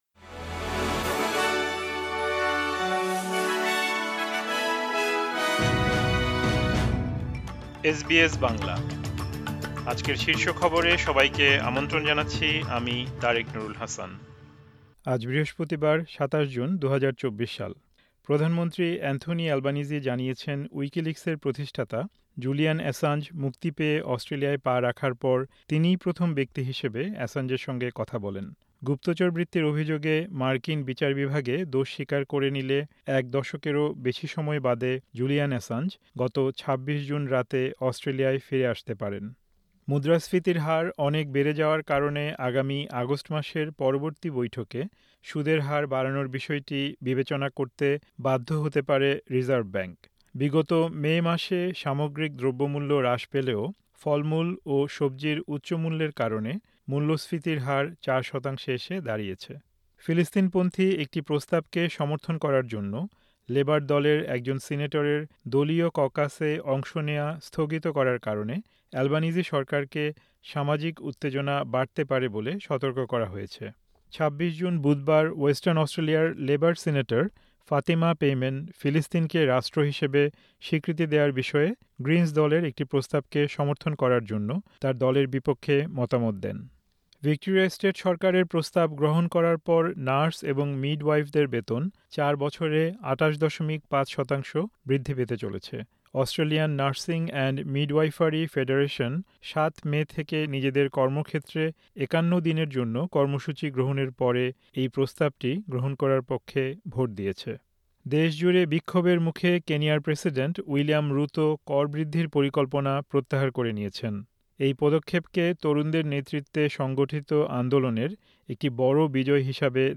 এসবিএস বাংলা শীর্ষ খবর: ২৭ জুন, ২০২৪